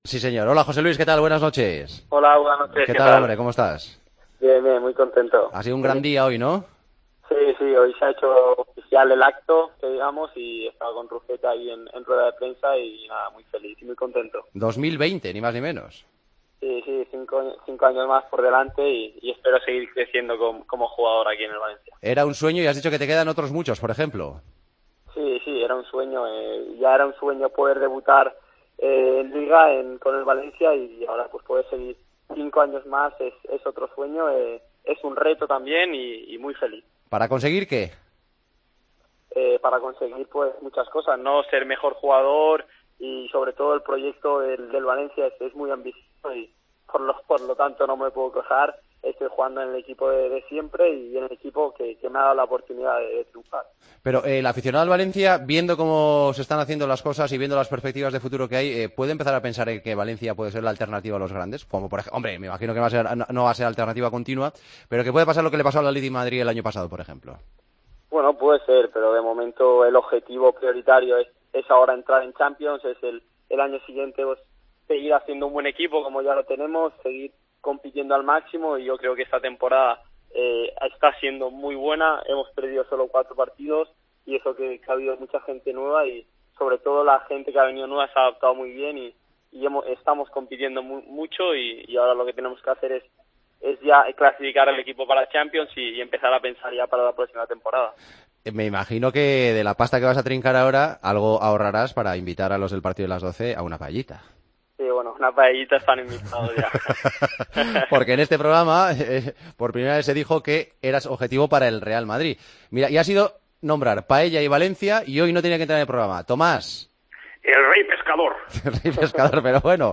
Hablamos con José Luis Gayà, renovado con el Valencia hasta 2020: "Renovar es un sueño y un reto para ser mejor jugador y, sobre todo, por el proyecto del Valencia, que es muy ambicioso.